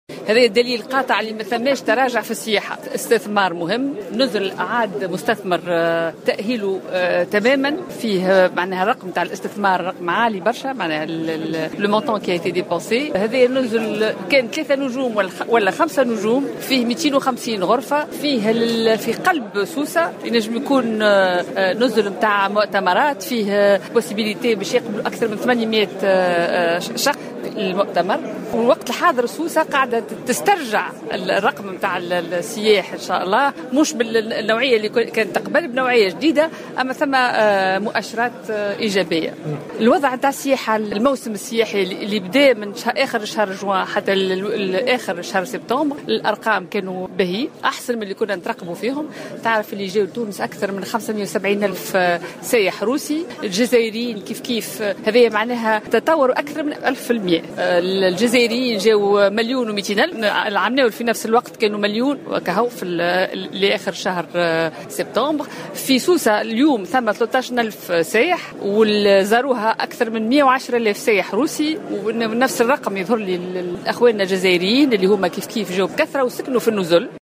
وأكدت اللومي، في تصريح للجوهرة أف أم، أن القطب السياحي بسوسة بدأ يسترجع أرقام السياح التي كانت تُحقق في السابق على الرغم من تغيّر نوعيتهم، (من بينهم 110 آلاف سائح روسي) وهي مؤشرات اعتبرتها الوزيرة إيجابية.